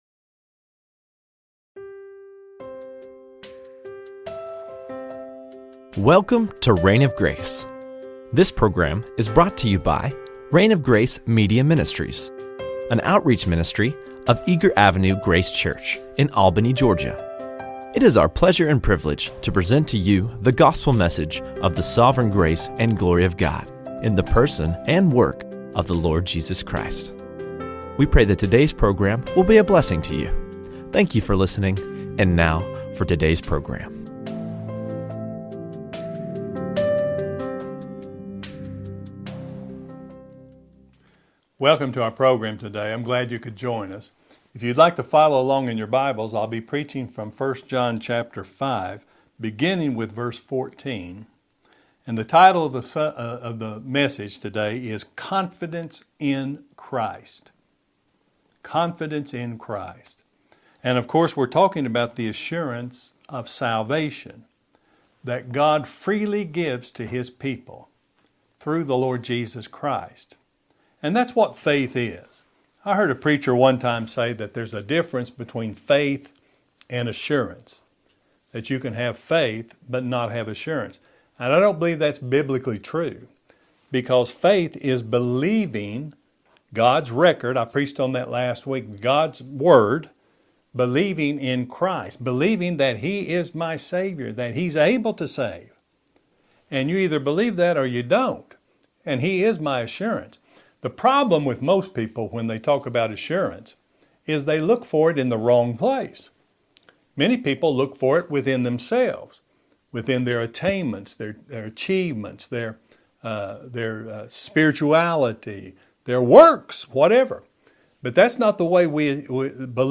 Confidence in Christ | SermonAudio Broadcaster is Live View the Live Stream Share this sermon Disabled by adblocker Copy URL Copied!